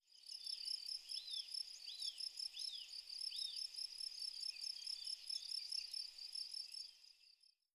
Cricket 3.wav